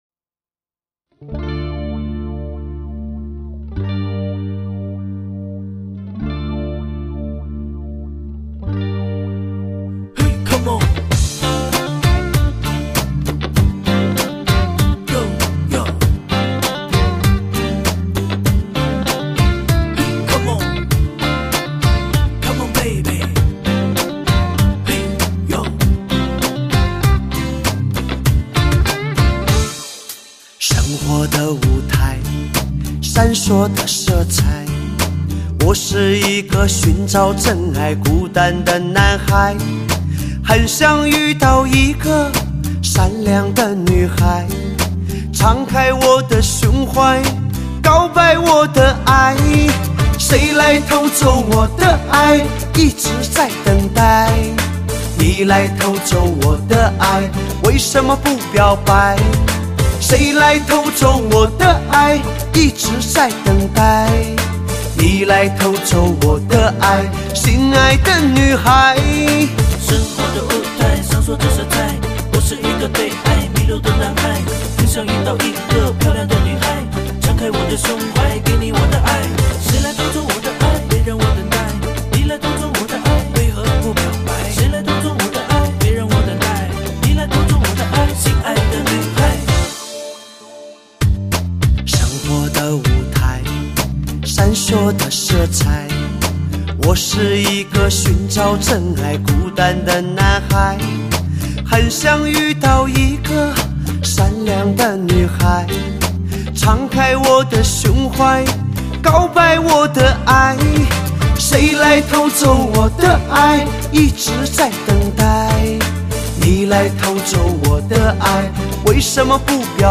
华语流行
嗓音沙而不哑，伤感透彻，磁性感浓烈。